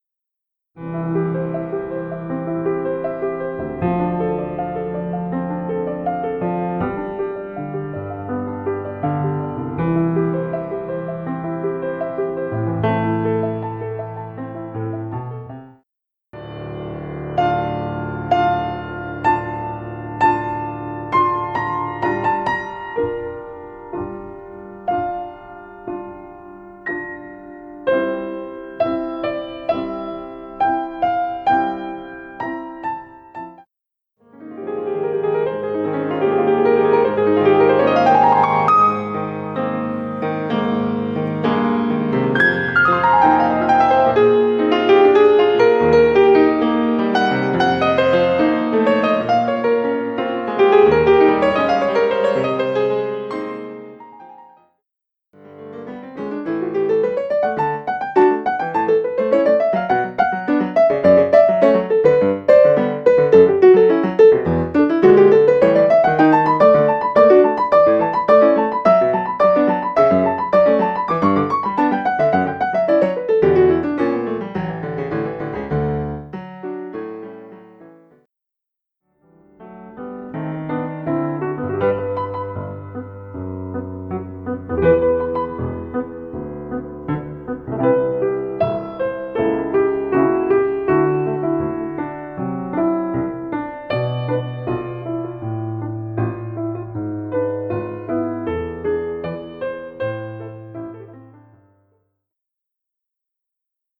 • Classical, jazz, and contemporary crossover styles